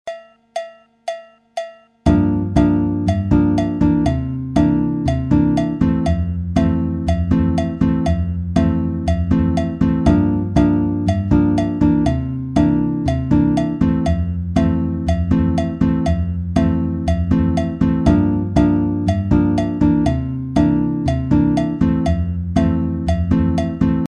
Comme support de rythme j'ai mis un clic à la croche, c'est à dire 2 pulsations par temps et cela dans le but de bien décortiquer la tourne de guitare.
batida avec syncope et anticipation